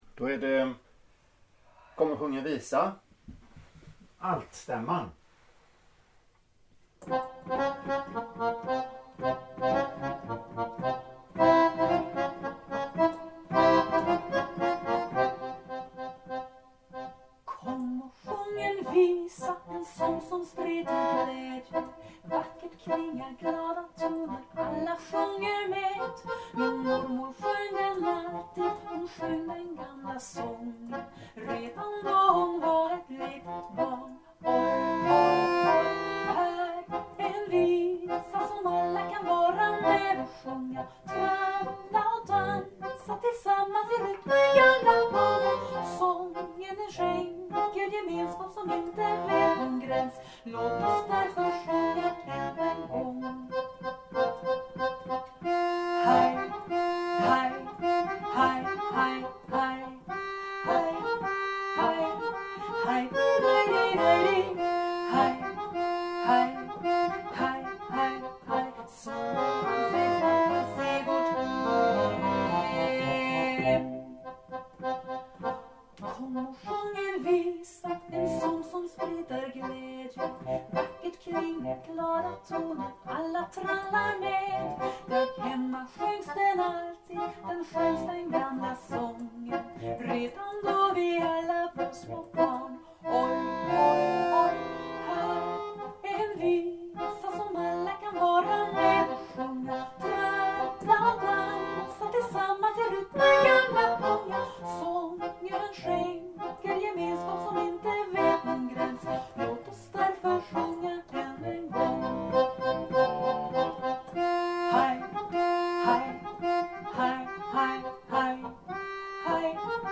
Kom och låt oss sjunga alt
Komochlatosssjunga_alt.mp3